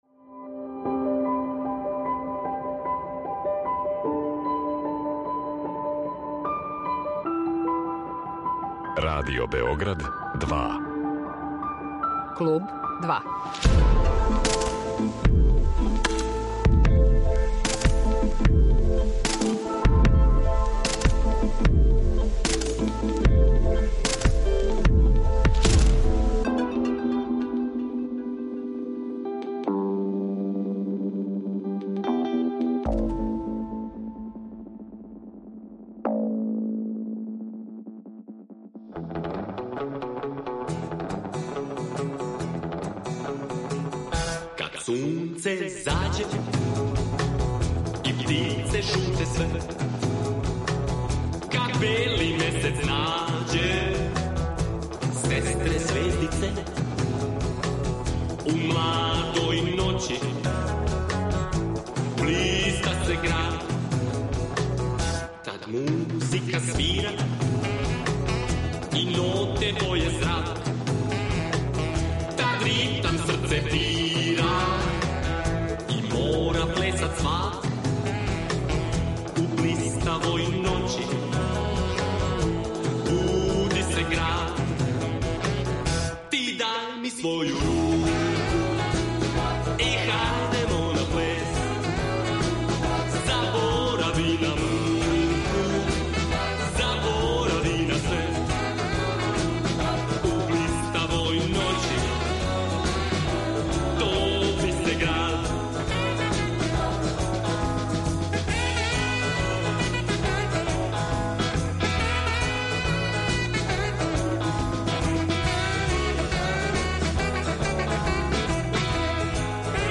Гост: музичар